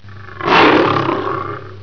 Le Lion (son cri est
Lion.wav